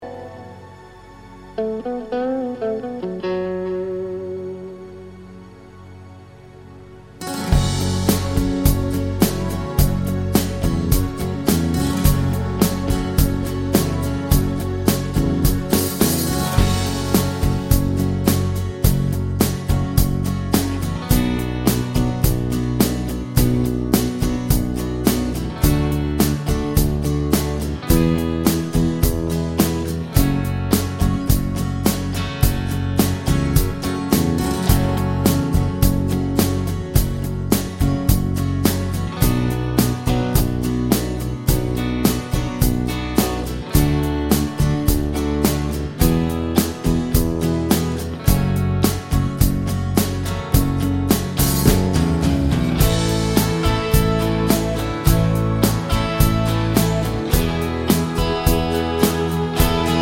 Intro Cut Pop (2010s) 4:30 Buy £1.50